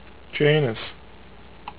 "JAY nus" ) is the sixth of Saturn's known satellites: